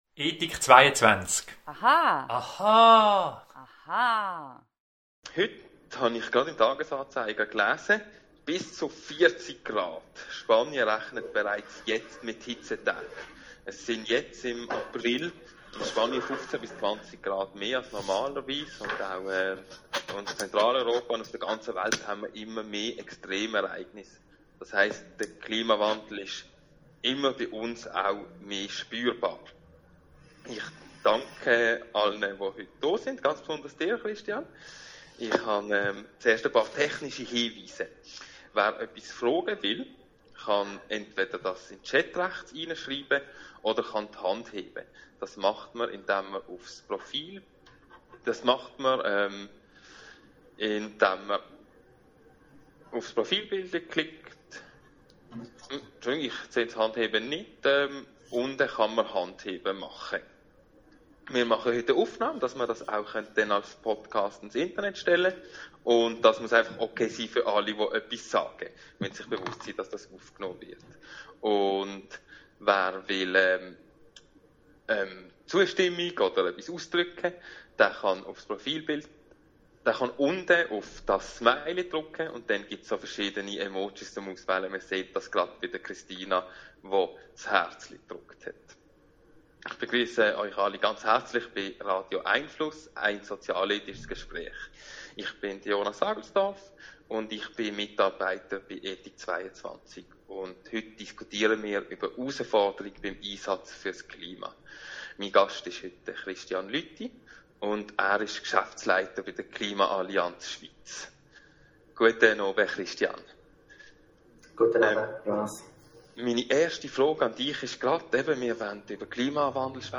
Radio🎙einFluss findet jeden Mittwochabend von 18.30 - 19 Uhr statt.
Bleiben Sie über die kommenden Radio🎙einFluss Audio-Gespräche informiert!